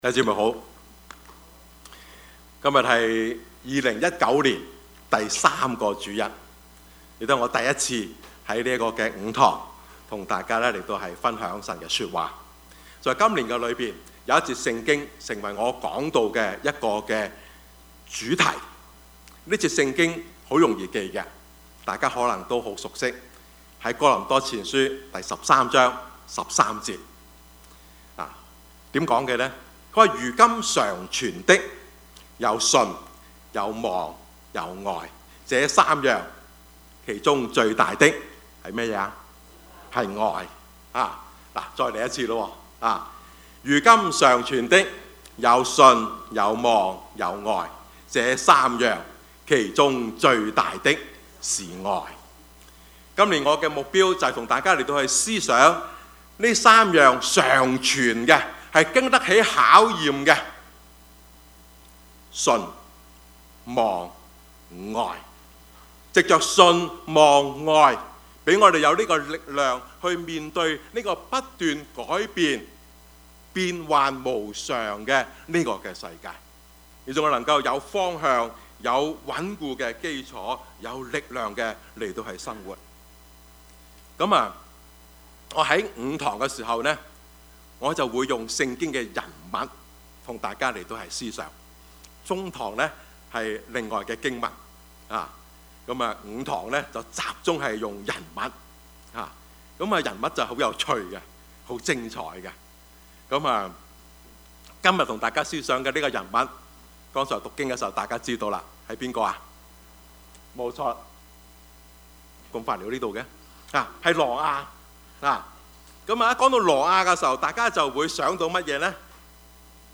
Service Type: 主日崇拜
Topics: 主日證道 « 網中人 大毛巾?